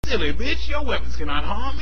Play, download and share Juggernaut weapons original sound button!!!!